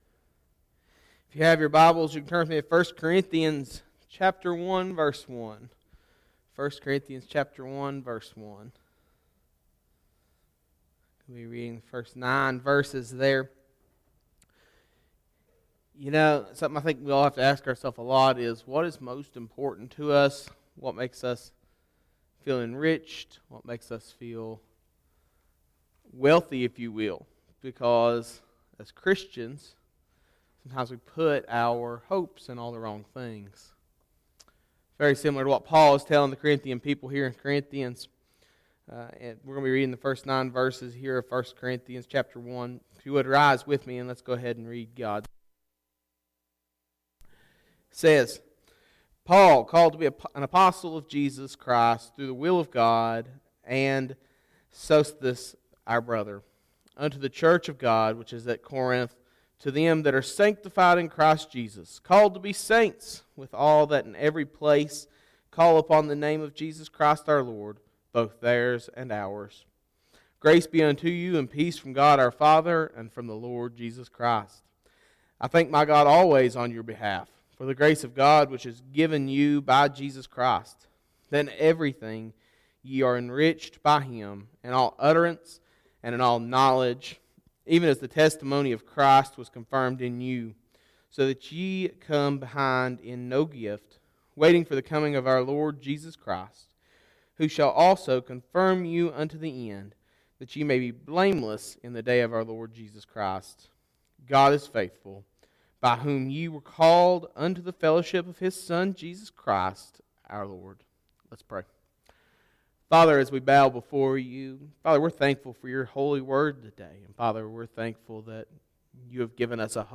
series: Sunday Morning Service